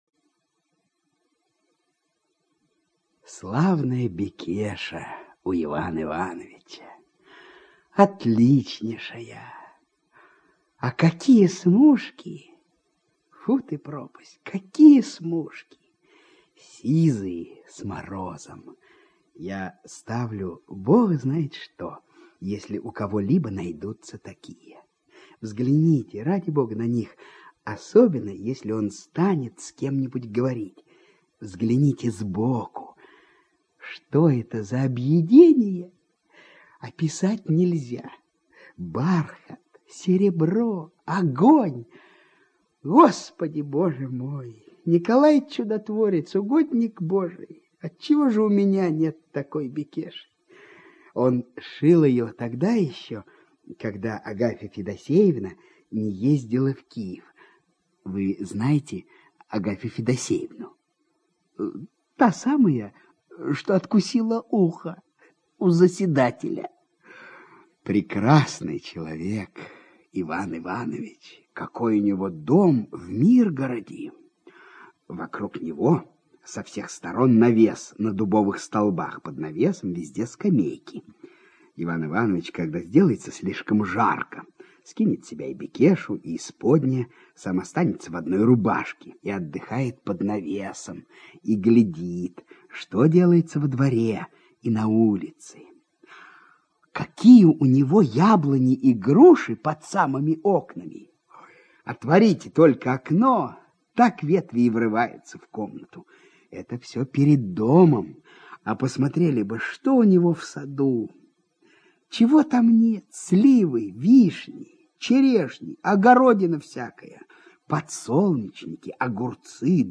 ЧитаетКалягин А.
ЖанрКлассическая проза